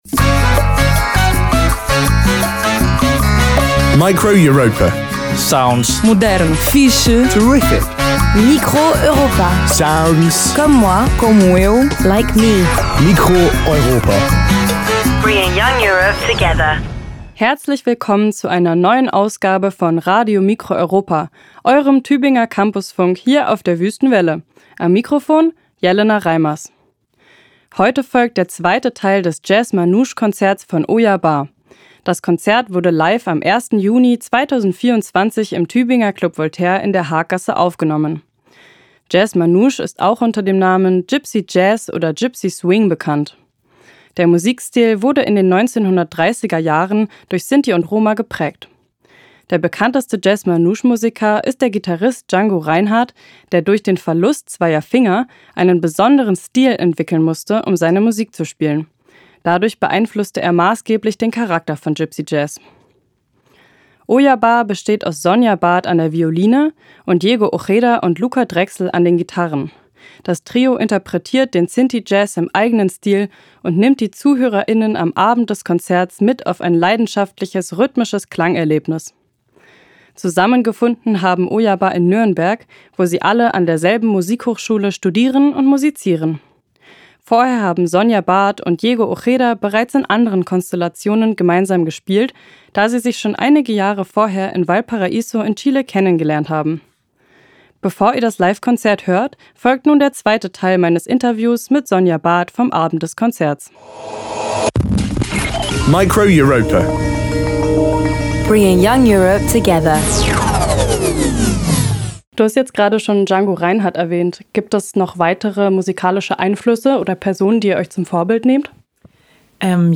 Geige
Gitarre
Form: Live-Aufzeichnung, geschnitten